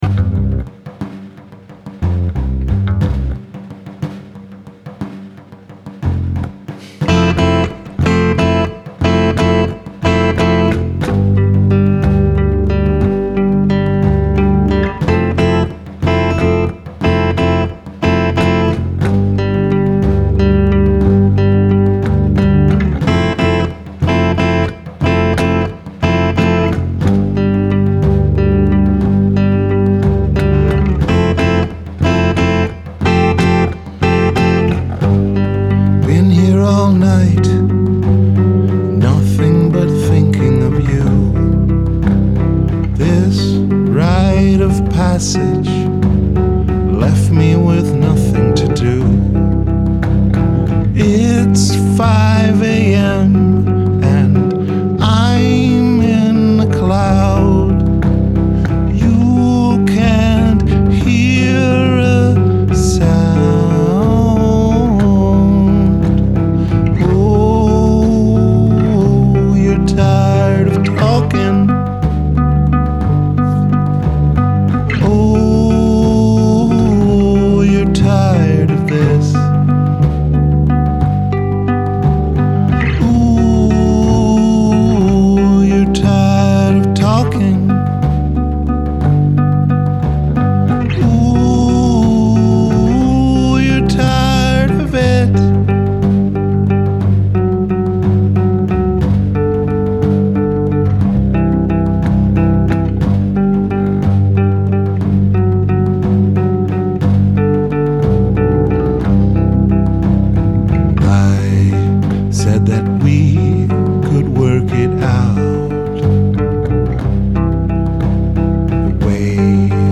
Rehearsals 20.2.2012